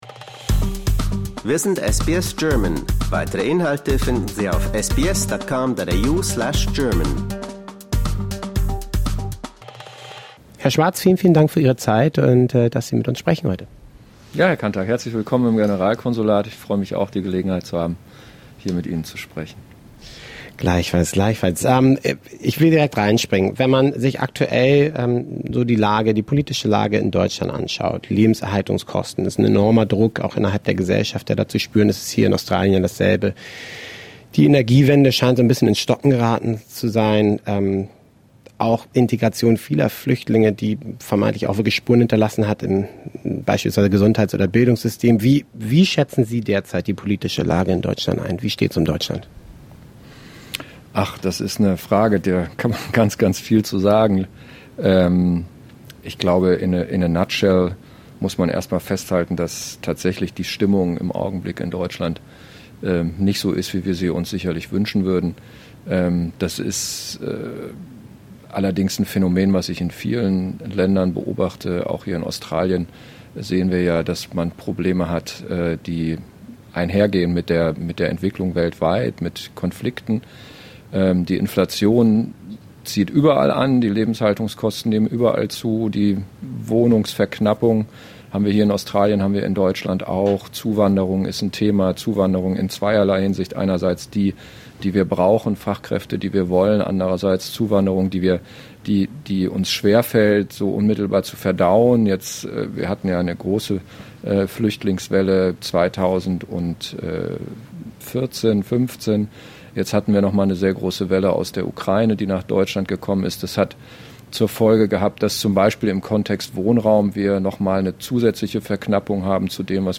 During an interview, the German Consul General in Sydney, Felix Schwarz, shares his views on current important topics. Among other things, the diplomat talks about the current political situation in Germany, the Ukraine conflict, Australian-German economic relations and Germany's geopolitical position.